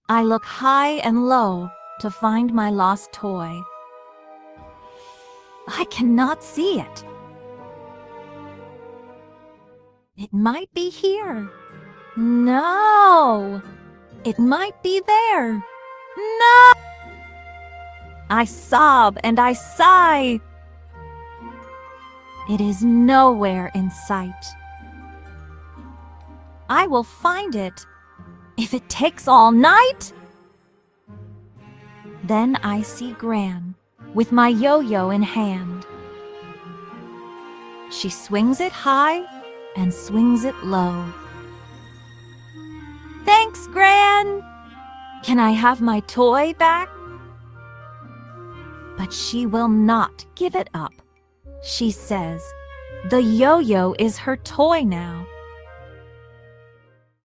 Free Mystery Decodable Reader with igh Words | Lost Toy